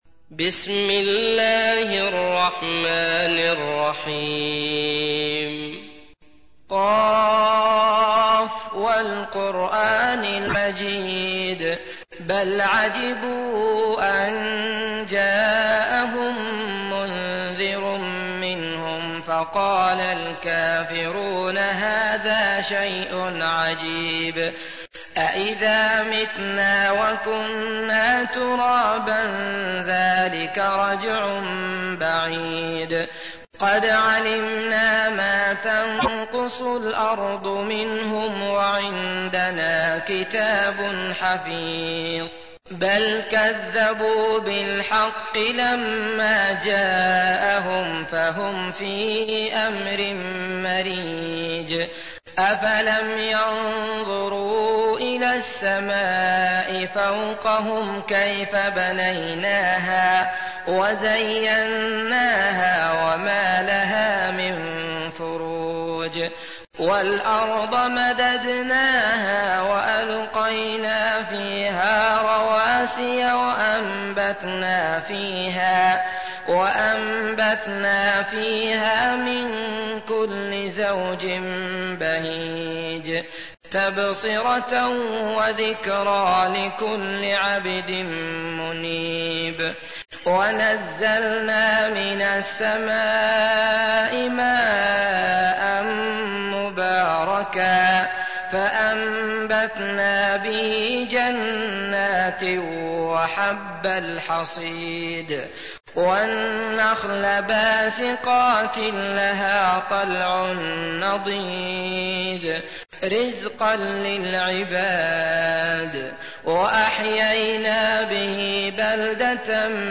تحميل : 50. سورة ق / القارئ عبد الله المطرود / القرآن الكريم / موقع يا حسين